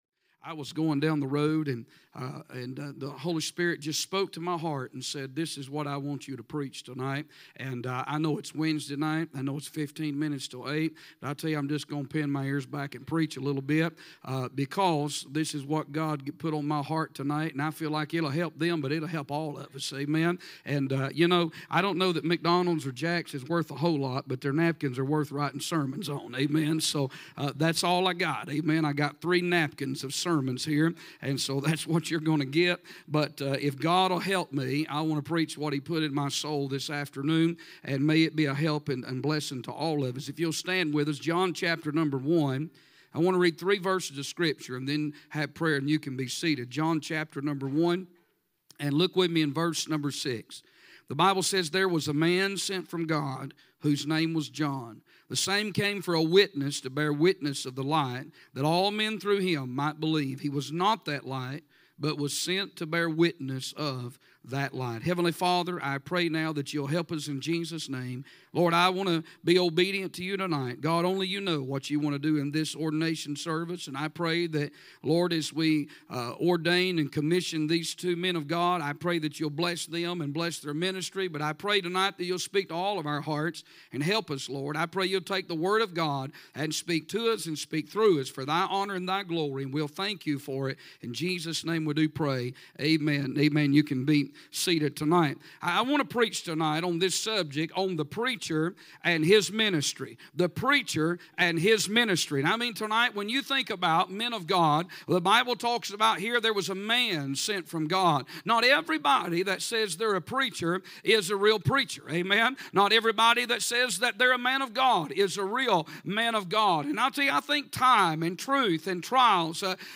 A sermon preached Wednesday Evening